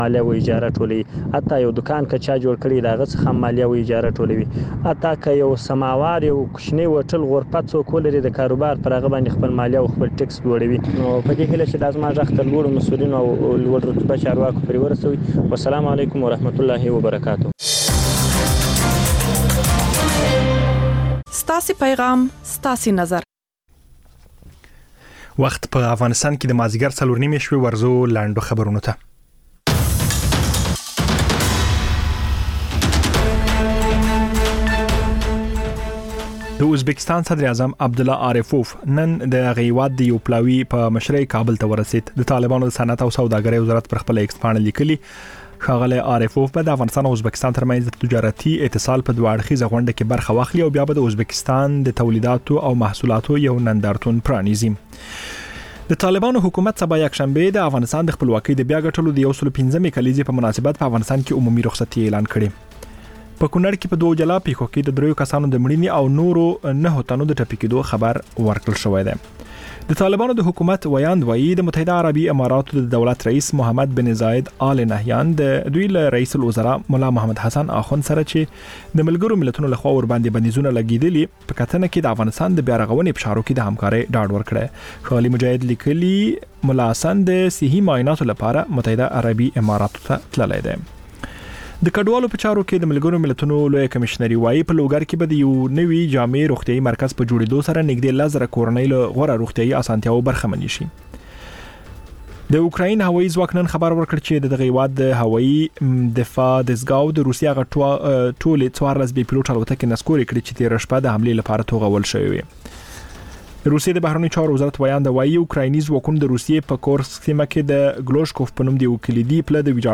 لنډ خبرونه - د زهرو کاروان (تکرار)